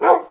bark2.ogg